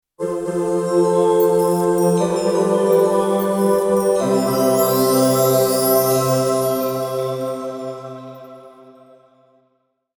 Divine Angelic Transition Sound Effect
An exalted angelic logo or transition sound effect featuring heavenly tones, soft choir textures, and shimmering harmonies. Perfect for intros, outros, logo reveals, spiritual themes, cinematic moments, fantasy projects, and uplifting transitions.
Genres: Sound Logo
Divine-angelic-transition-sound-effect.mp3